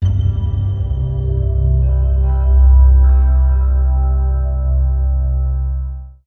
Synth Impact 01.wav